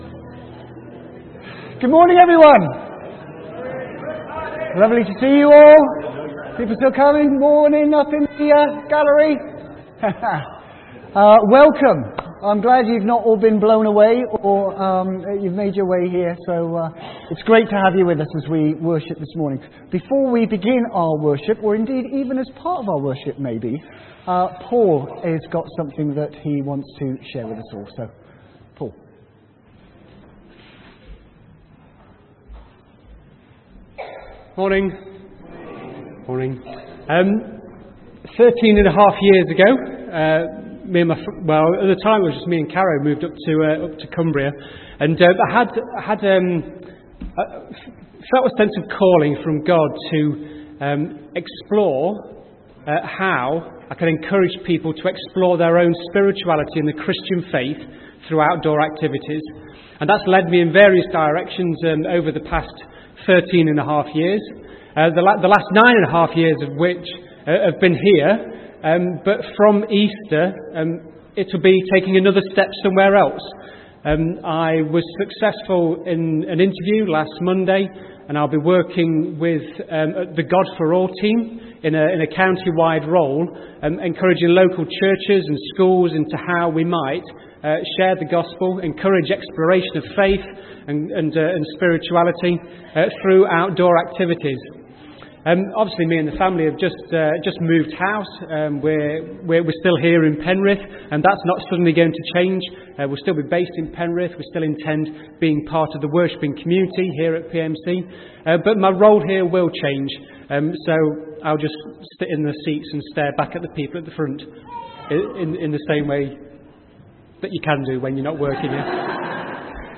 A message from the series
From Service: "10.45am Service"